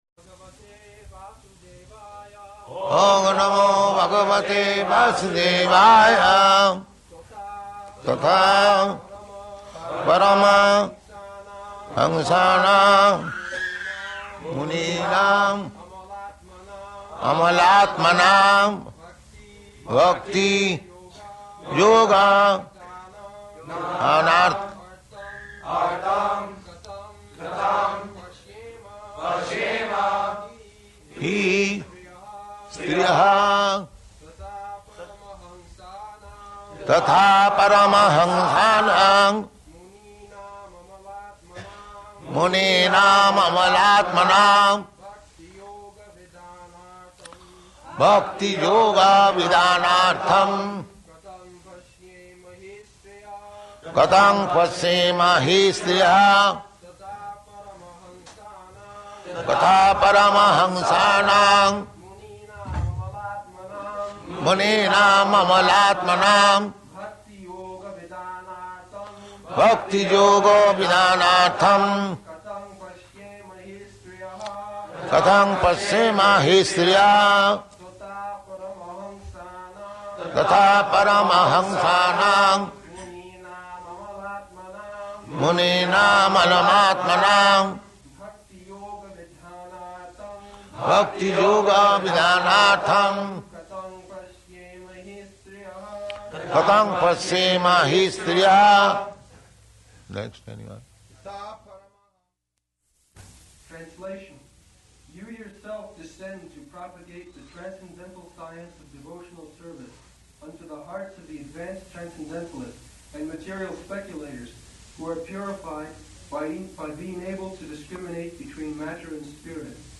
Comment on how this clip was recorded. April 12th 1973 Location: New York Audio file